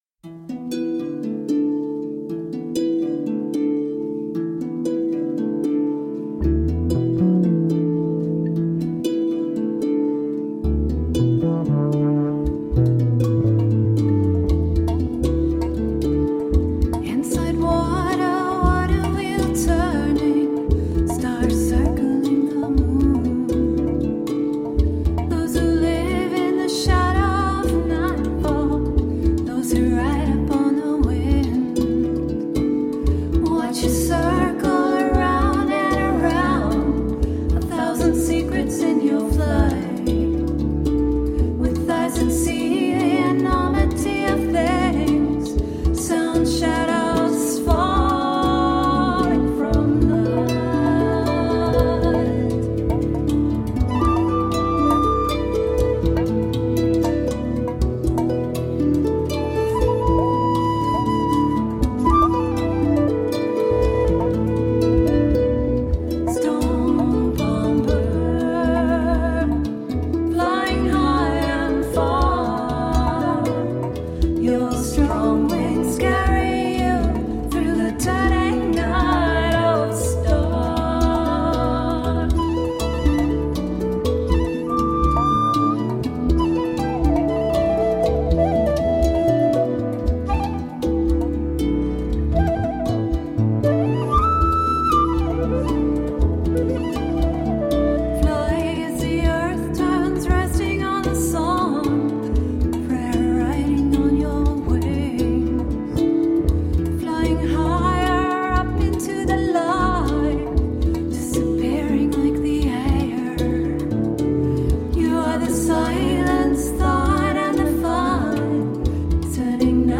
Power harp, other-worldly vocals and global percussion.
Tagged as: World, New Age, Harp